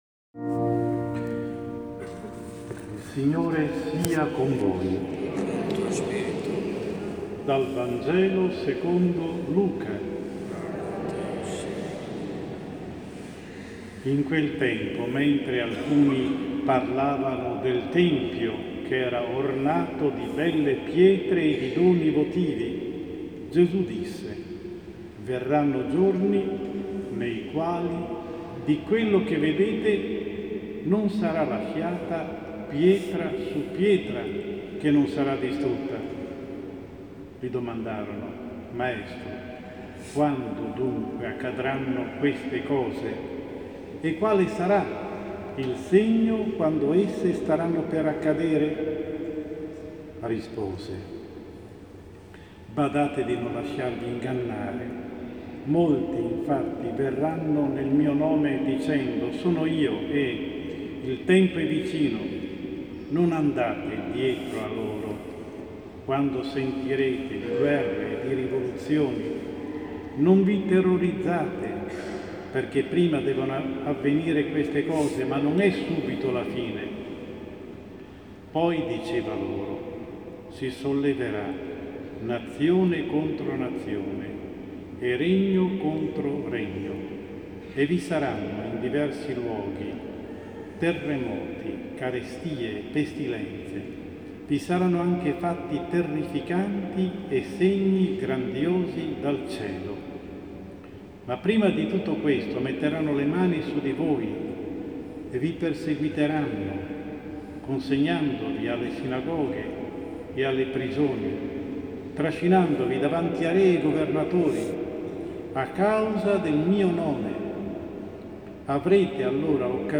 Omelia Domenica XXXIII anno C – 13 Novembre 2016